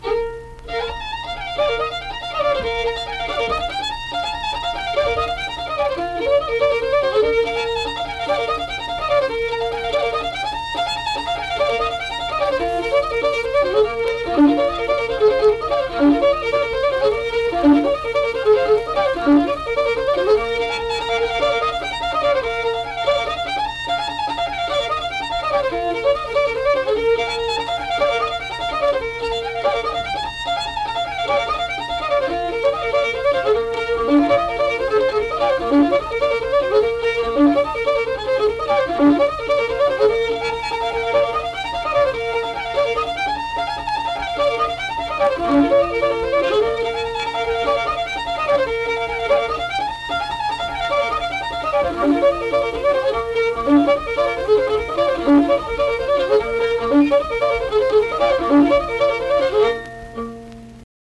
Unaccompanied fiddle music and accompanied (guitar) vocal music performance
Instrumental Music
Fiddle
Braxton County (W. Va.)